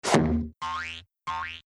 teleport_reappear.mp3